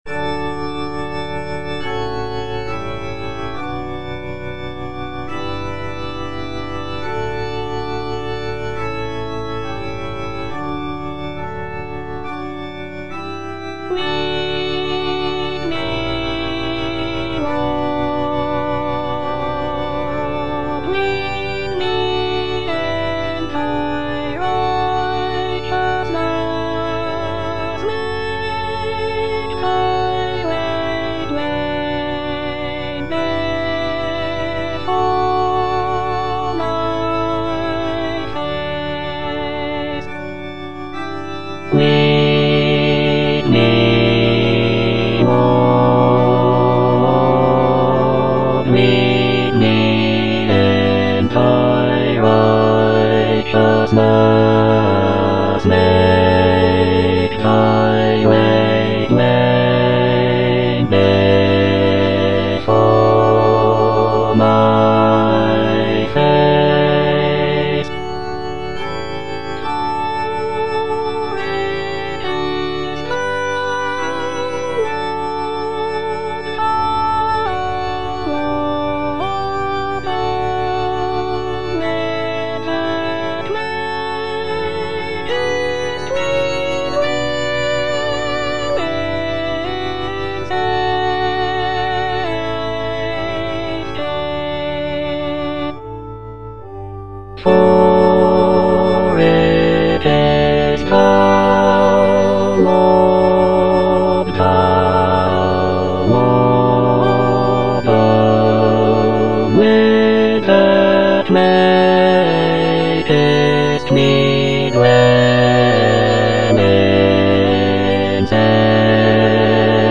S.S. WESLEY - LEAD ME, LORD Bass (Emphasised voice and other voices) Ads stop: auto-stop
"Lead me, Lord" is a sacred choral anthem composed by Samuel Sebastian Wesley in the 19th century.
The music is characterized by lush choral textures and expressive dynamics, making it a popular choice for church choirs and worship services.